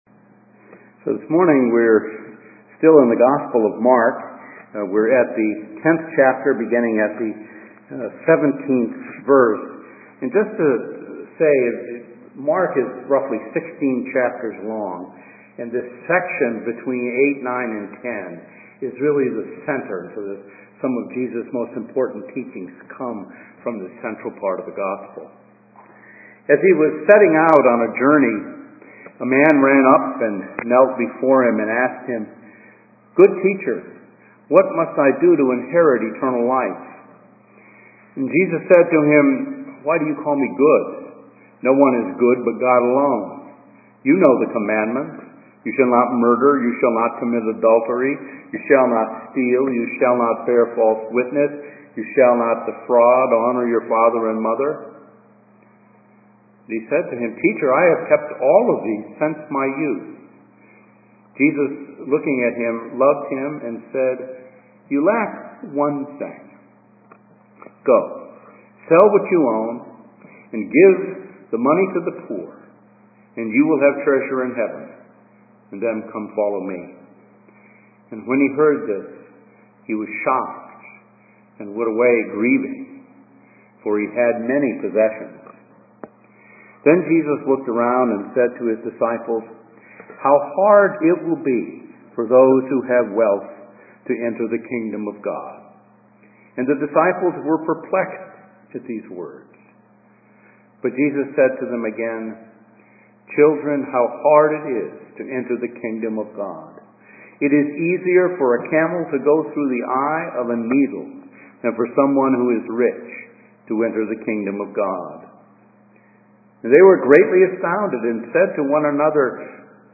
A message from the series "Lectionary."